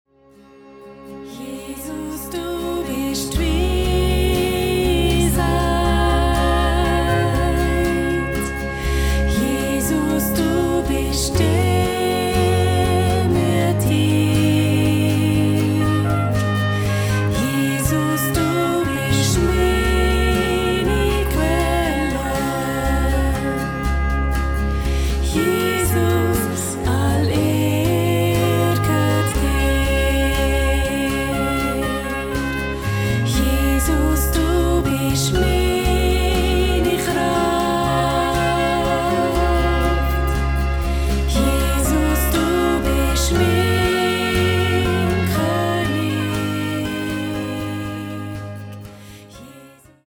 Ihre sanften Lieder in 432Hz
verbreiten eine Atmosphäre von Frieden und Zuversicht